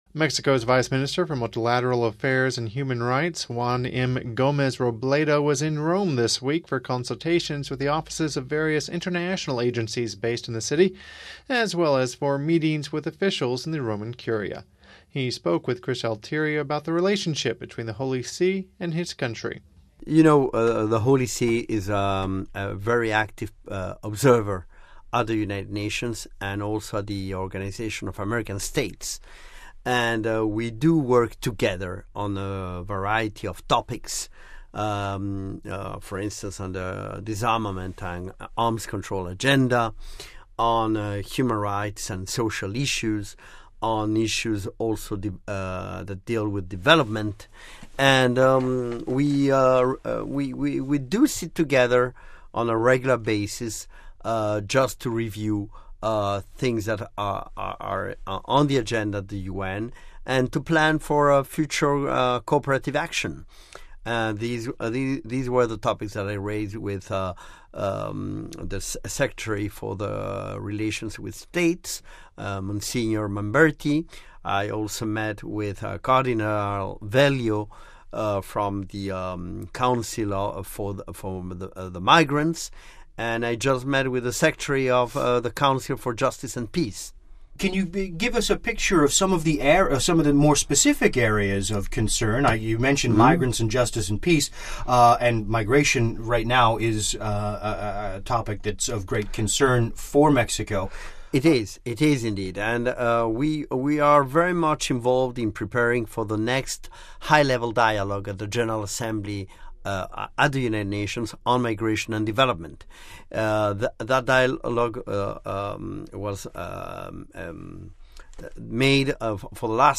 He visited Vatican Radio to talk about the relationship between the Holy See and his country.
extended conversation